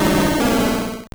Cri de Cerfrousse dans Pokémon Or et Argent.